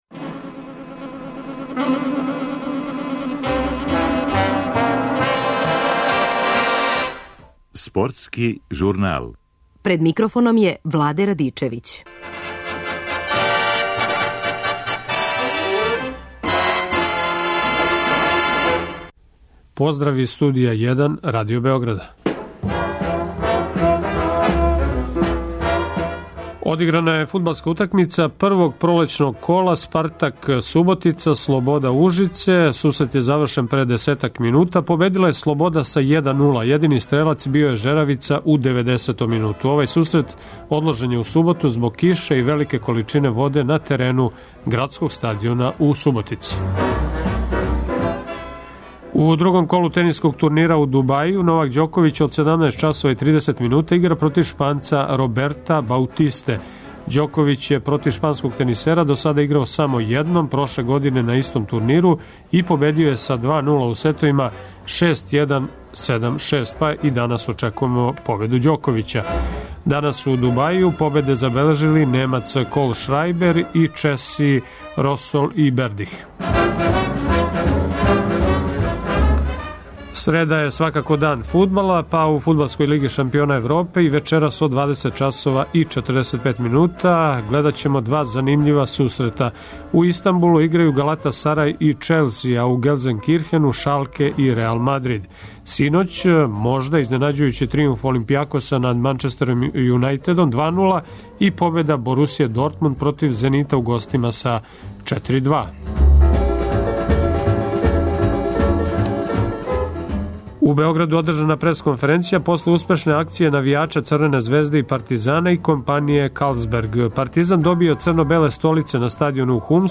Гост коментатор Слободан Сантрач.